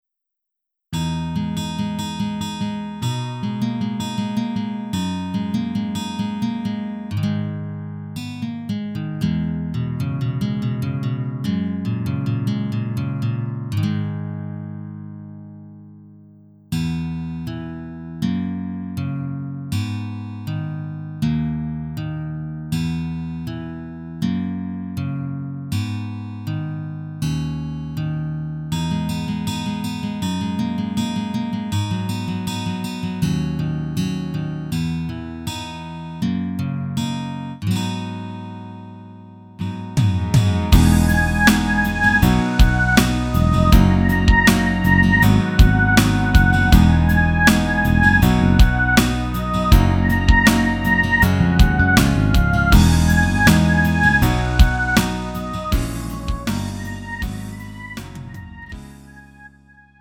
음정 -1키 3:39
장르 구분 Lite MR